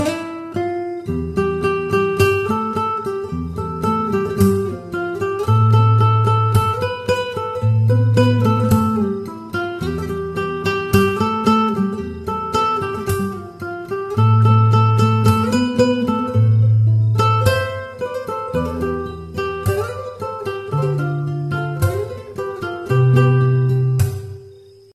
زنگ خور شیک عاشقونه برای گوشی